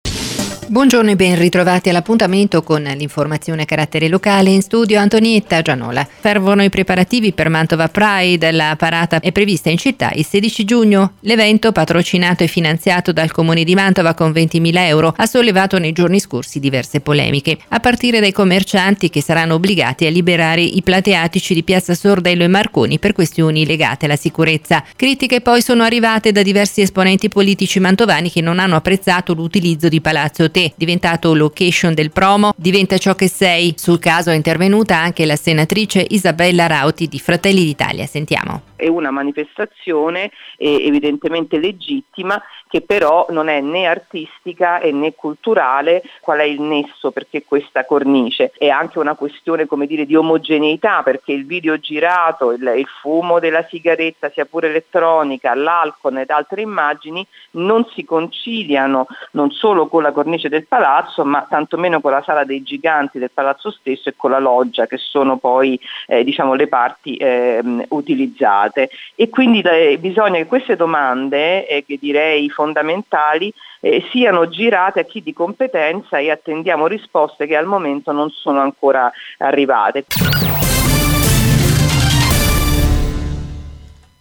Radio Bruno – Intervista telefonica sul Mantova Pride